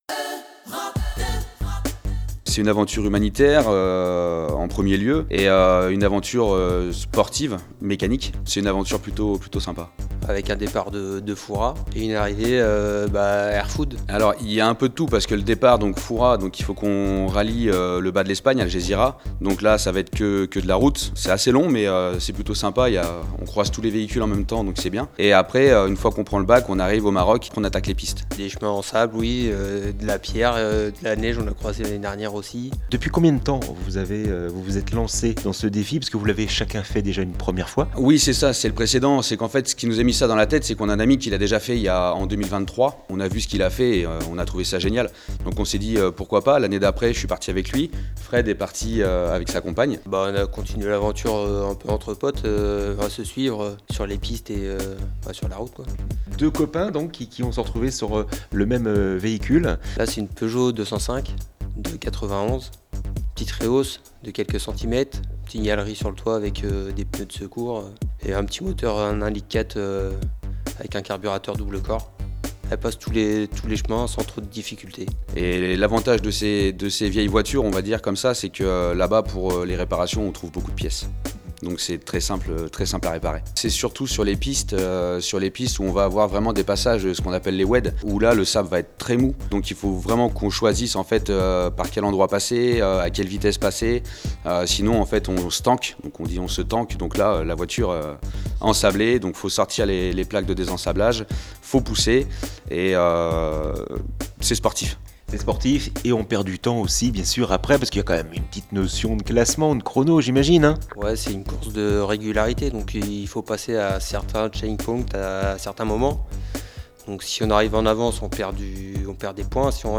Le duo vendéen